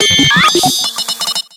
Audio / SE / Cries / PORYGONZ.ogg